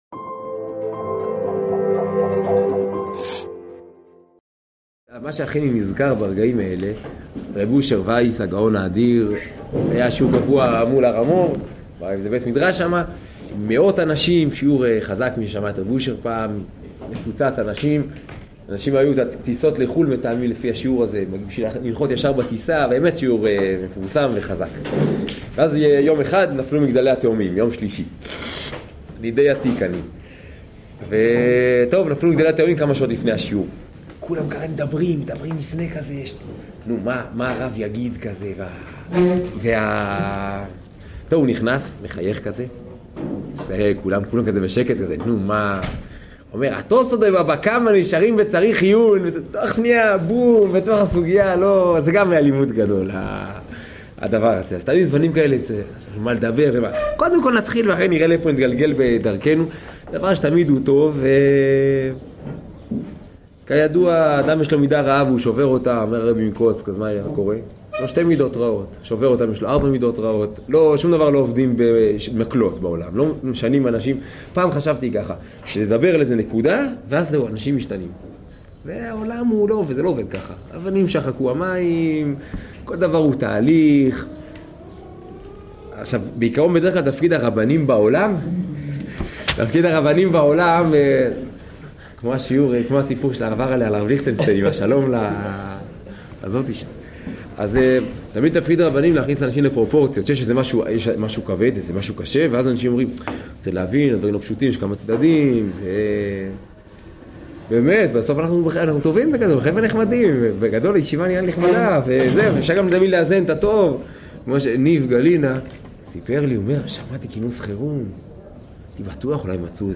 שיעור חיזוק בנושא לימוד תורה וחיזוק השאיפות בתורה ובקודש.